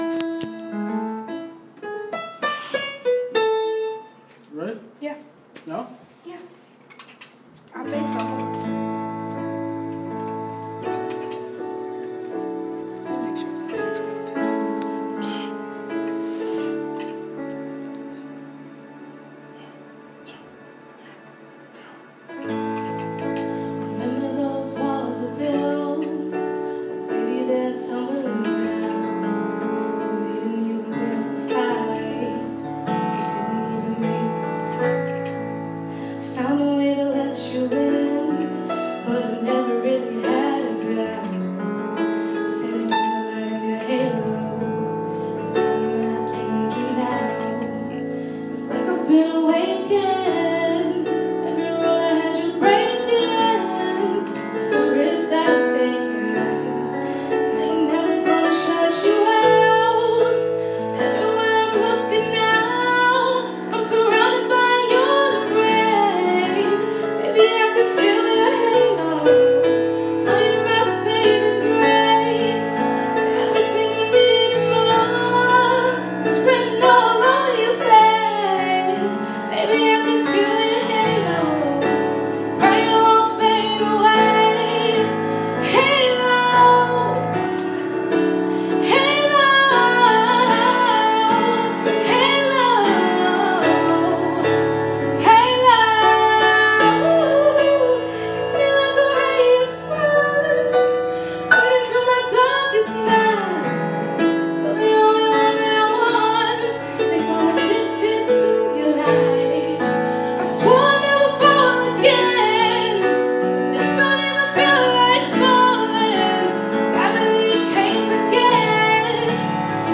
Vocals
Pianist